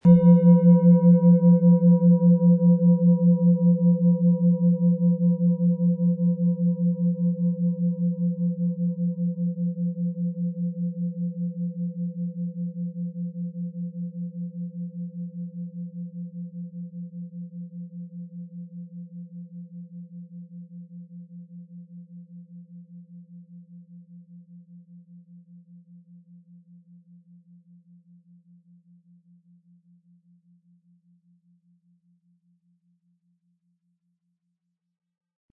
Sonne & Platonisches Jahr Klangschale Ø 17,5 cm im Sound-Spirit Shop | Seit 1993
Nach uralter Tradition von Hand getriebene Planetenklangschale Sonne.
• Tiefster Ton: Platonisches Jahr
SchalenformBihar
MaterialBronze